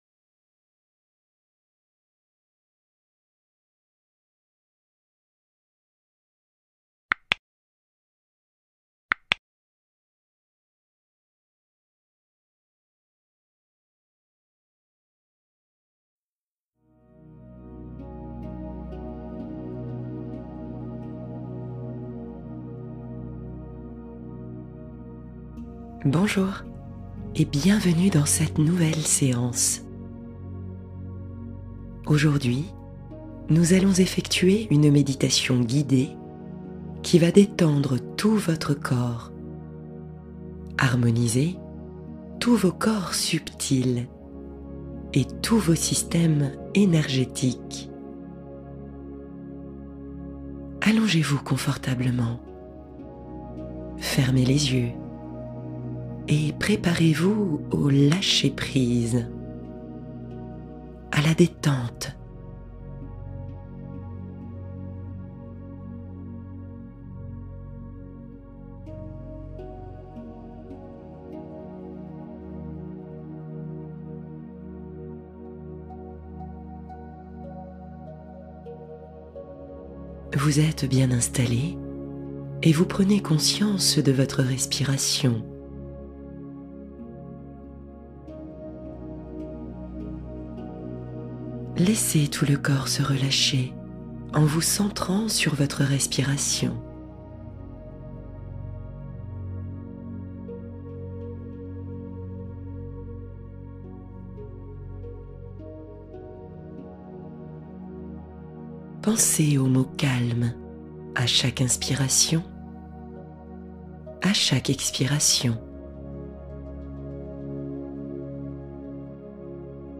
Relaxation de Noël : rêverie apaisante pour tous les âges